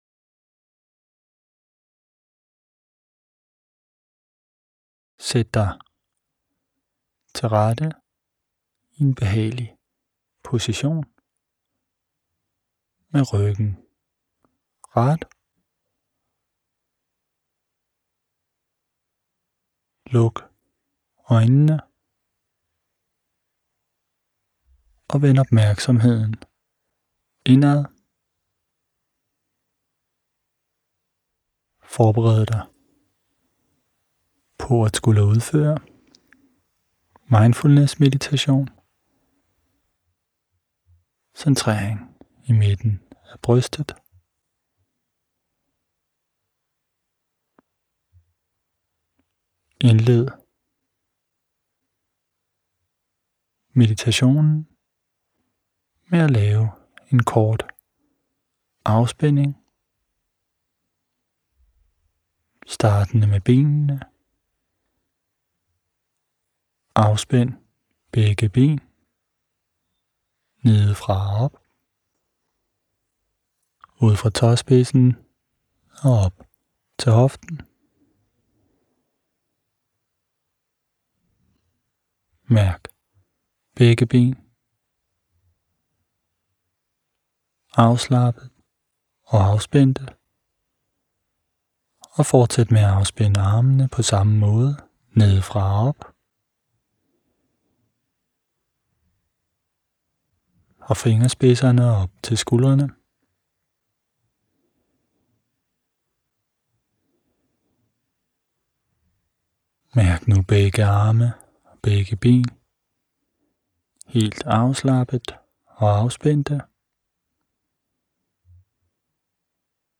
04-Mindfulness-Meditation-Centrering-i-Midten-af-Brystet.mp3